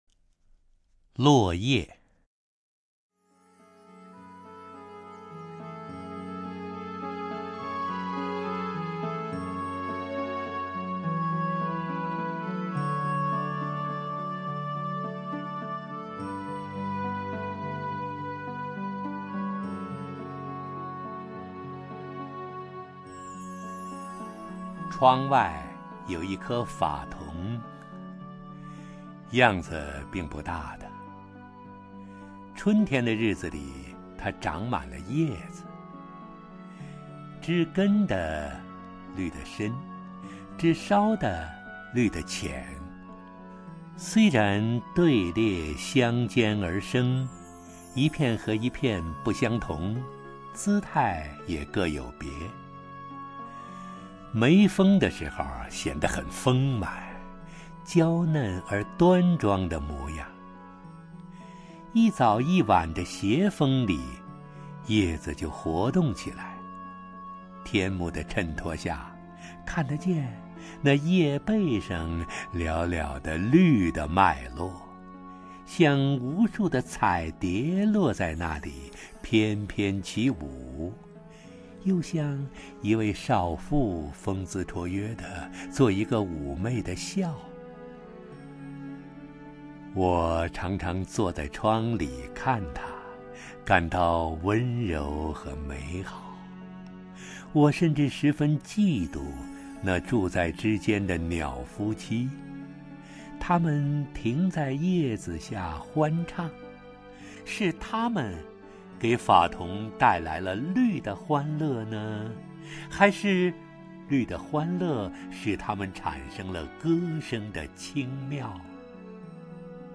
首页 视听 名家朗诵欣赏 陈醇
陈醇朗诵：《落叶》(贾平凹)
LuoYe_JiaPingWa(ChenChun).mp3